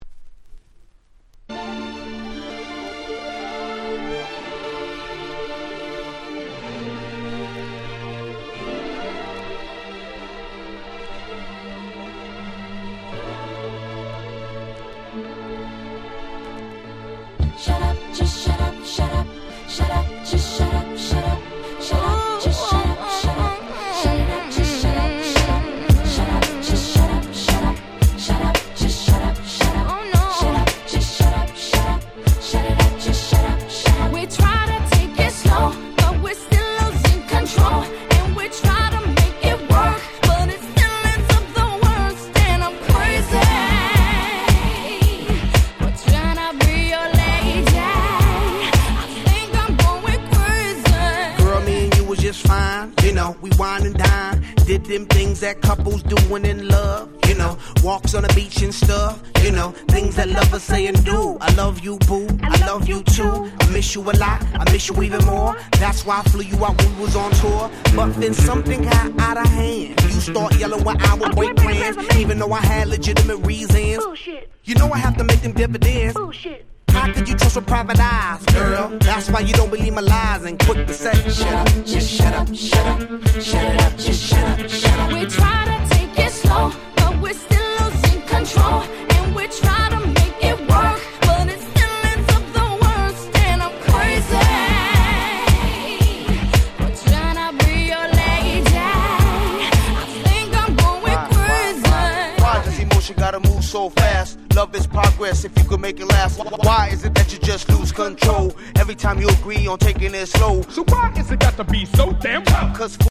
03' Super Hit Hip Hop !!